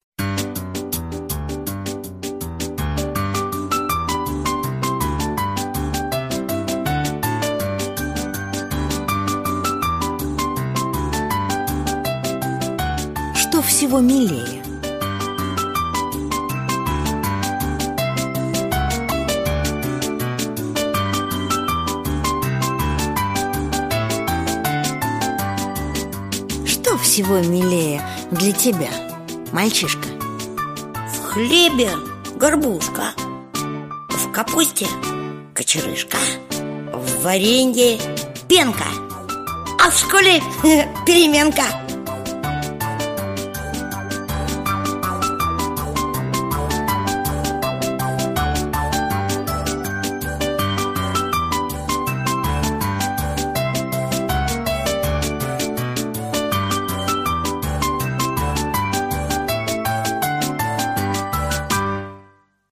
На данной странице вы можете слушать онлайн бесплатно и скачать аудиокнигу "Что всего милее" писателя Валентин Берестов.